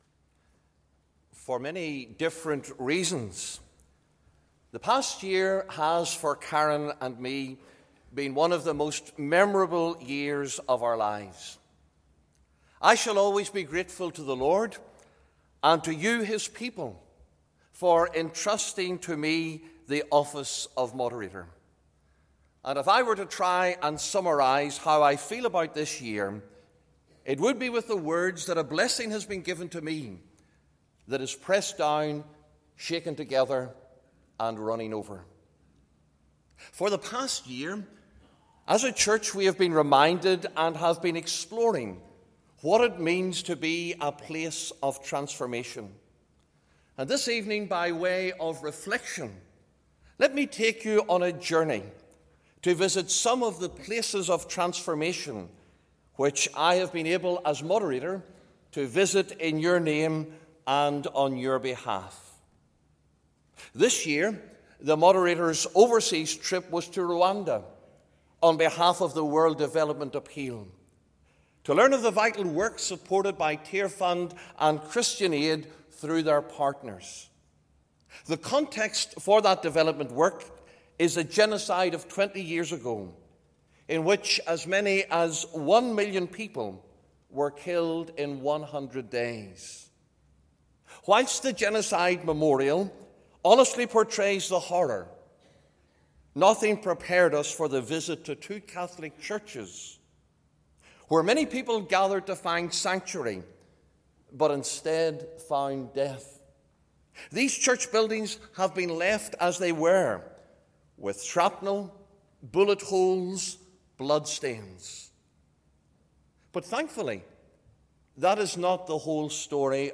The outgoing Moderator, Dr Rob Craig, reflects on his year in office at the Opening Meeting of the 2014 General Assembly.
The Assembly met in Assembly Buildings, Belfast from Monday, 2nd June until Thursday, 5th June, 2014.